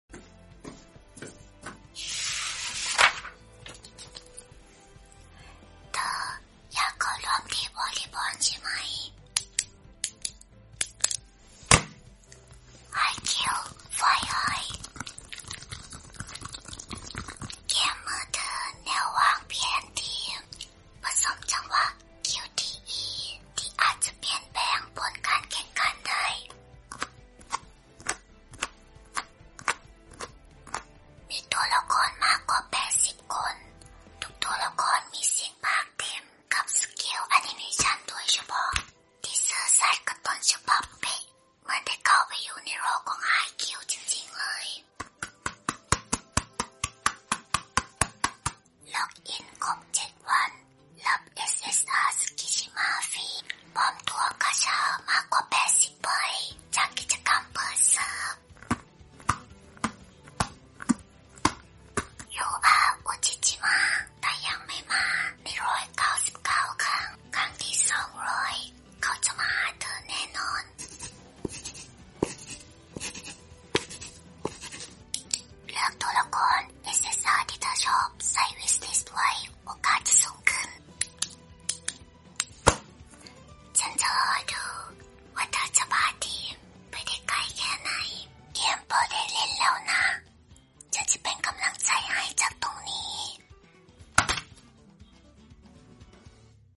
🏐The Sound Of Volleyball It Sound Effects Free Download